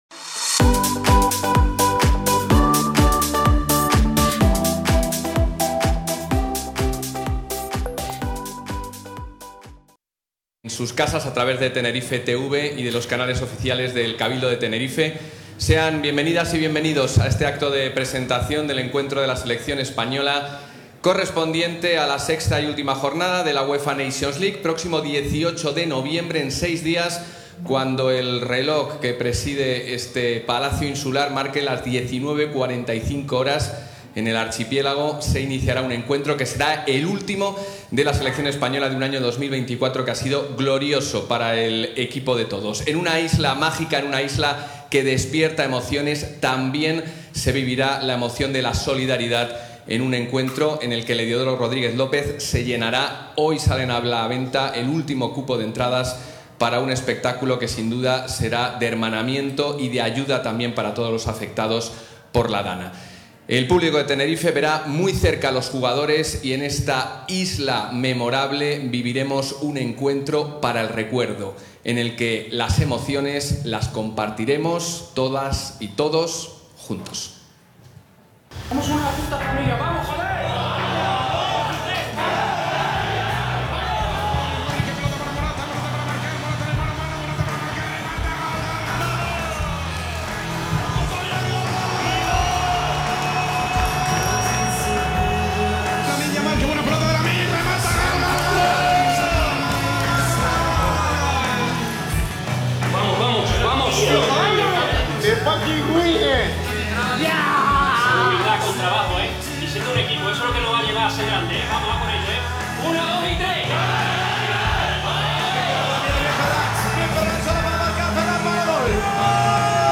El Salón Noble del Cabildo de Tenerife, acogió este martes, 12 de noviembre, la presentación del partido oficial de la UEFA Nations League, que enfrentará a la Selección Absoluta de España, frente a la de Suiza, en el partido correspondiente al...